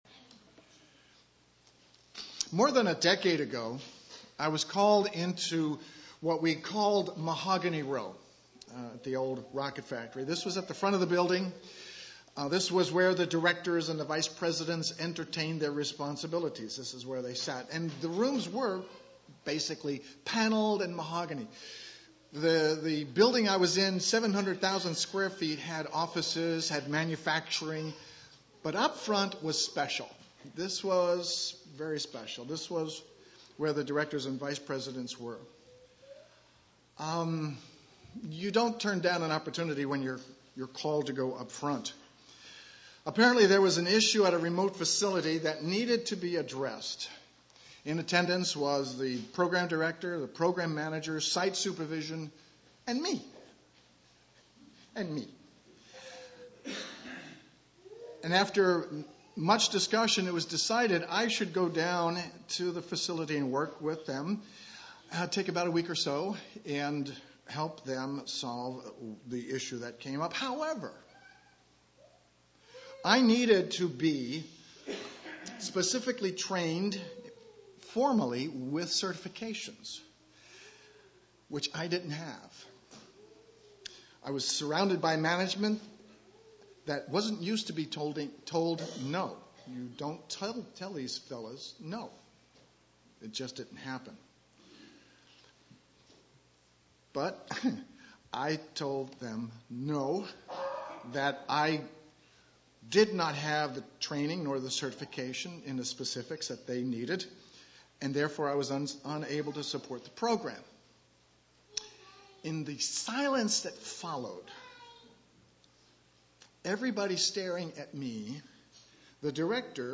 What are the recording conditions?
Given in Eureka, CA